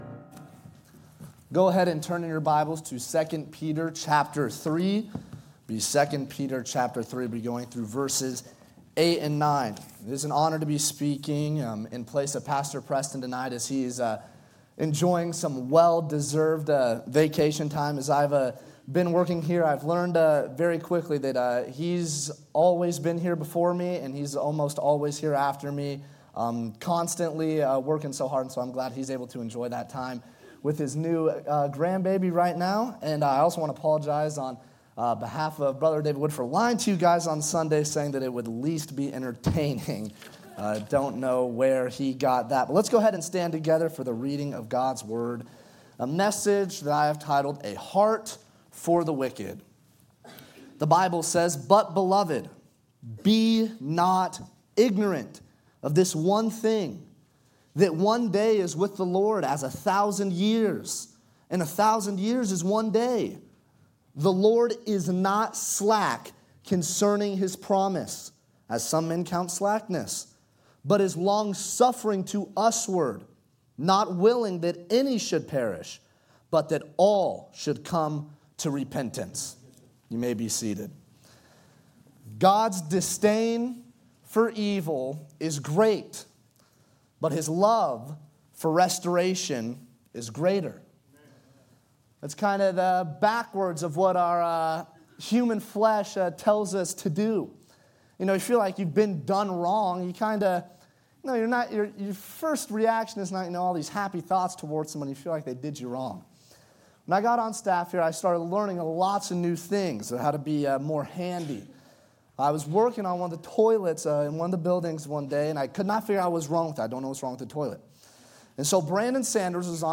" Guest & Staff Preachers " Guest & Staff Preachers at Bethany Baptist Church Scripture References: 2 Peter 3:8-9